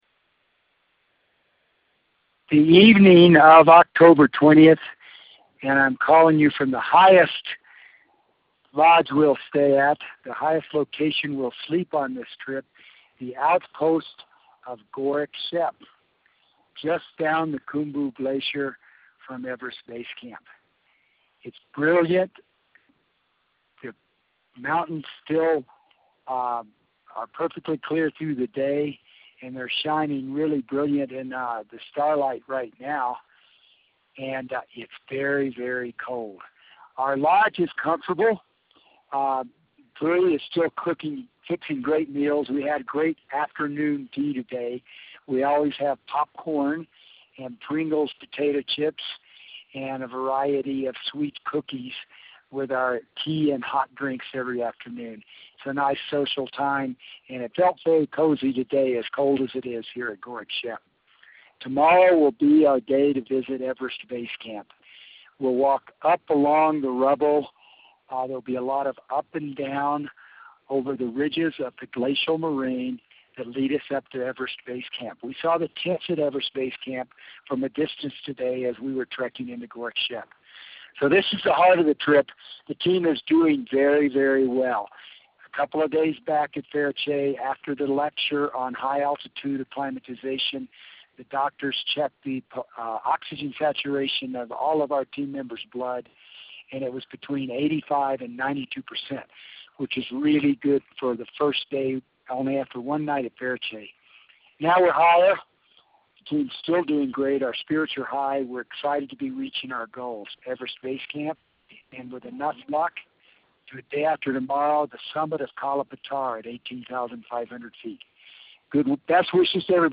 Everest Base Camp Trek Dispatch